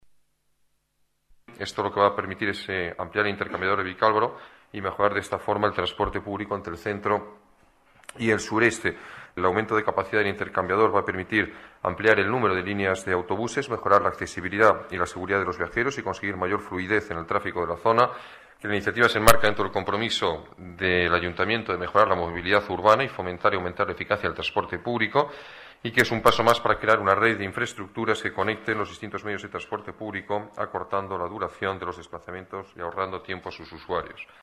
Nueva ventana:Declaraciones alcalde, Alberto Ruiz-Gallardón: intercambiador Puerta de Arganda